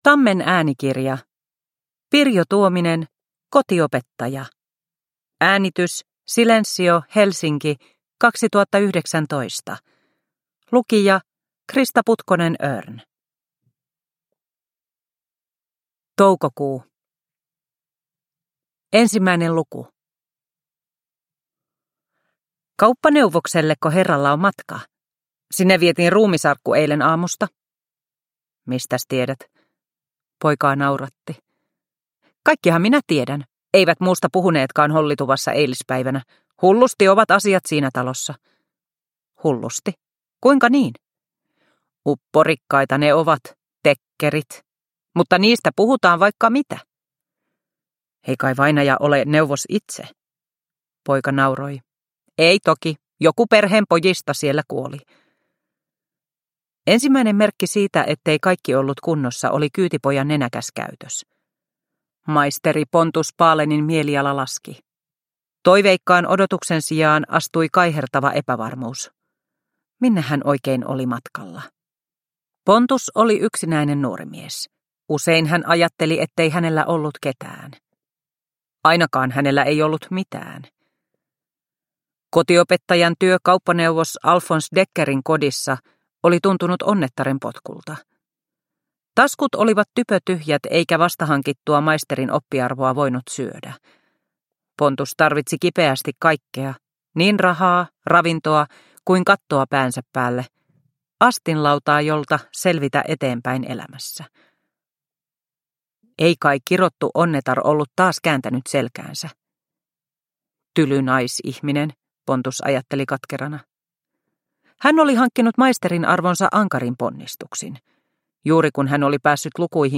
Kotiopettaja – Ljudbok – Laddas ner